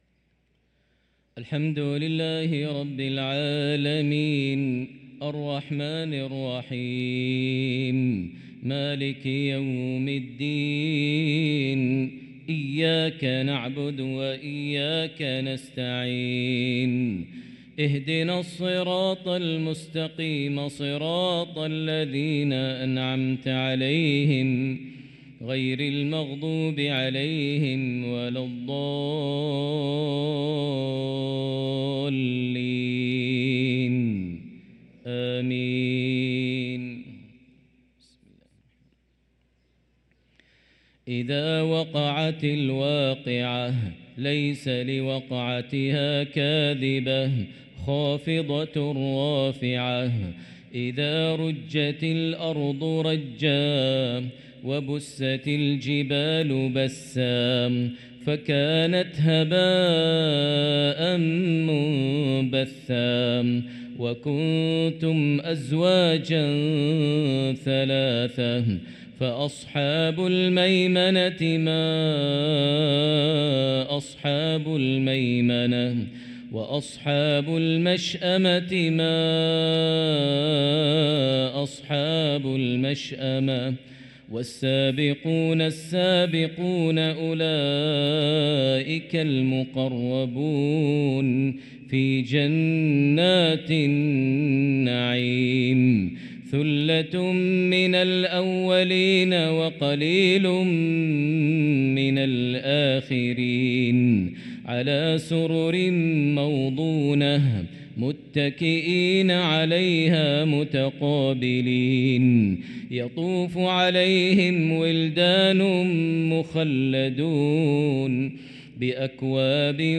صلاة العشاء للقارئ ماهر المعيقلي 17 جمادي الأول 1445 هـ
تِلَاوَات الْحَرَمَيْن .